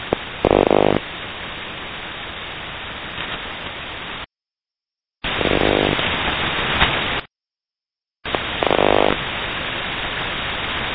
8992 USB HF-GCS French Data Mode
UNID Burst Tone
This is a recording sample from when this signal first began.
Rufisque-Unid-Burst_Tone.ogg